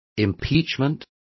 Complete with pronunciation of the translation of impeachment.